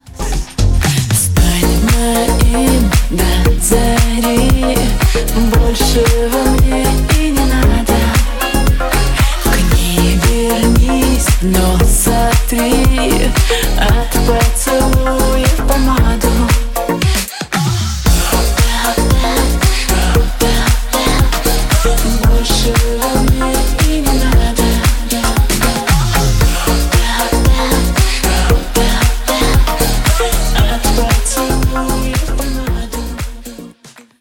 • Качество: 160, Stereo
поп
чувственные
вздохи